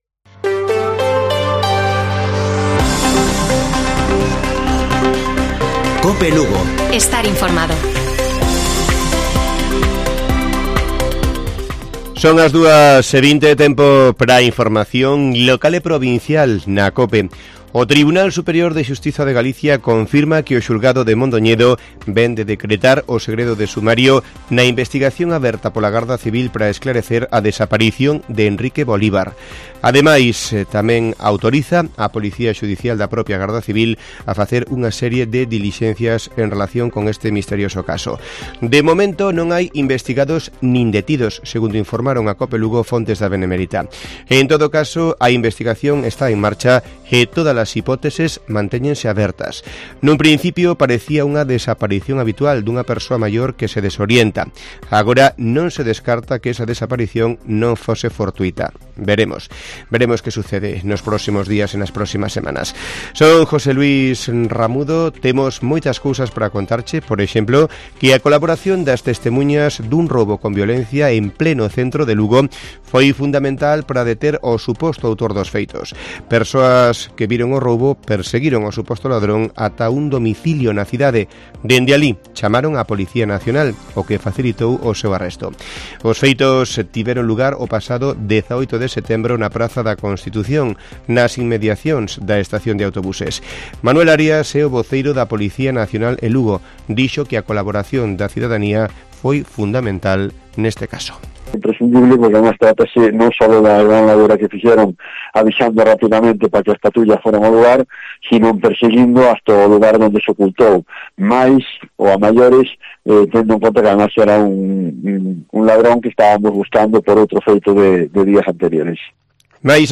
Informativo Mediodía de Cope Lugo. 22 DE SEPTIEMBRE. 14:20 horas